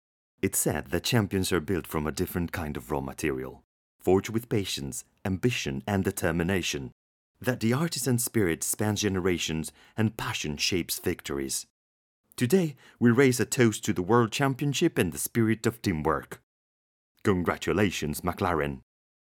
Locucion-McLaren-ENG-001_Correcto.mp3